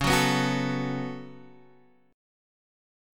Db7sus4#5 chord